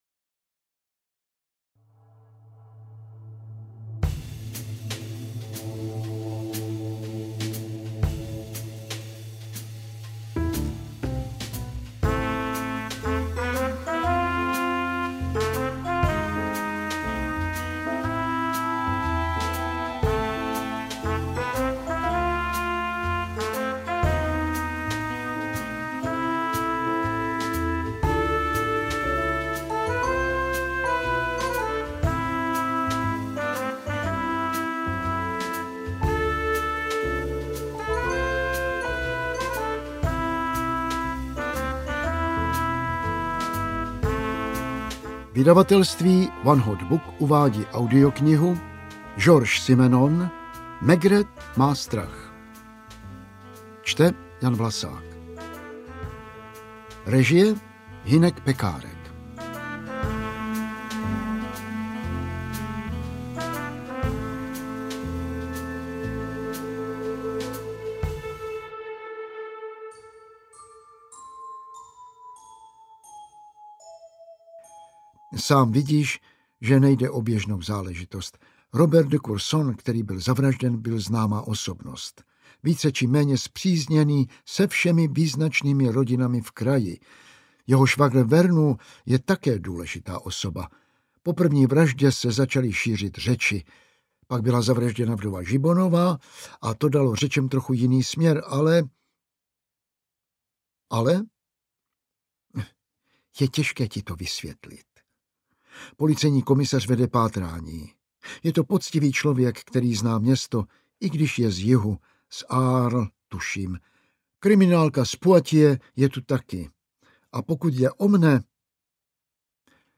Ukázka z knihy
maigret-ma-strach-audiokniha